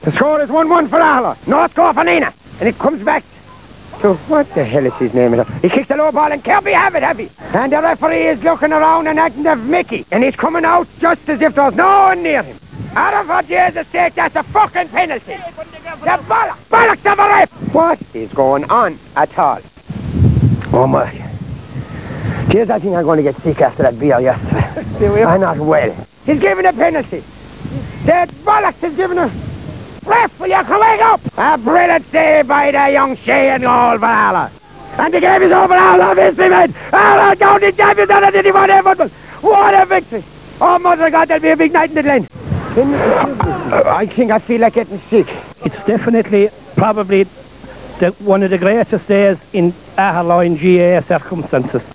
Gaa Match Commentry Stress Reliever